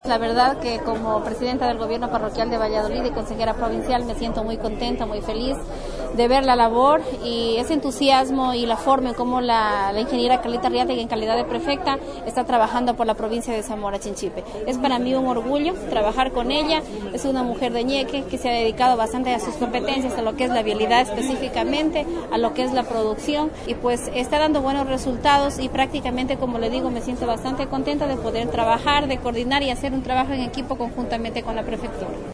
ANA RODRÍGUEZ, PRES, GAD VALLADOLID